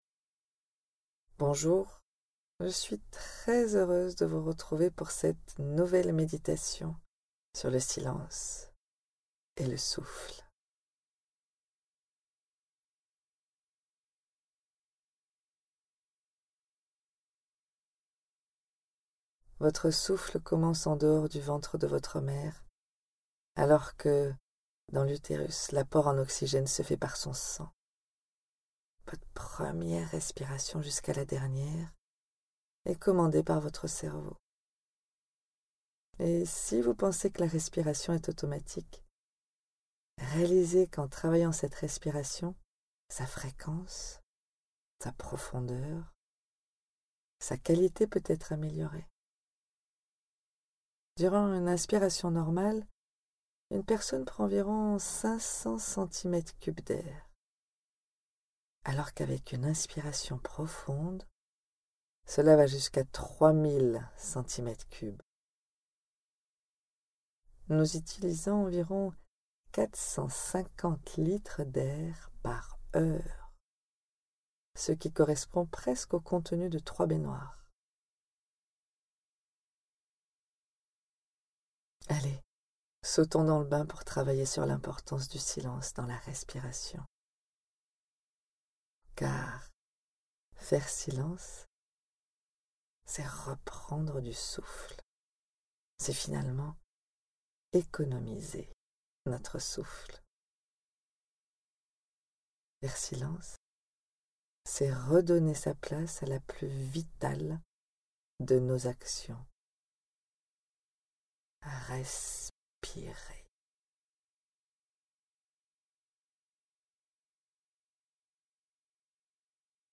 Méditations guidées
REC_MEDITATION_FEVRIER_SEANCE_4.mp3